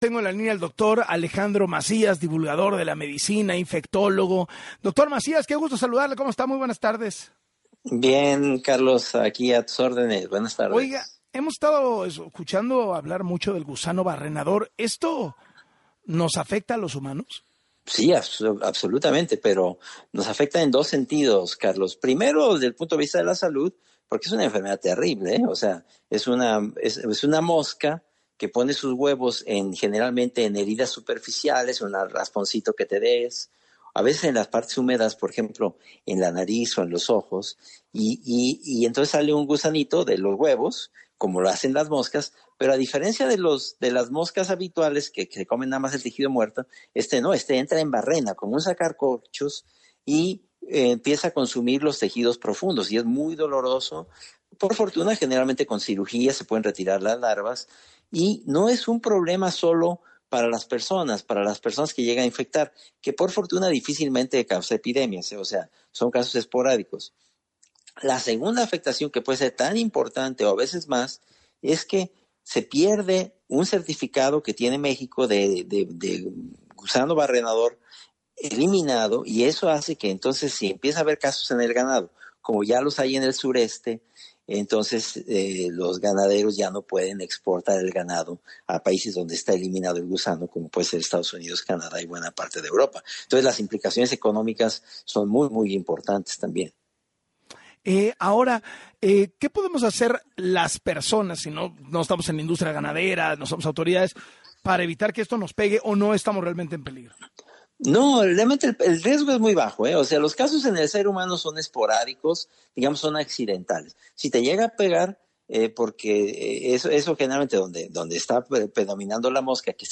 En entrevista con Carlos Loret de Mola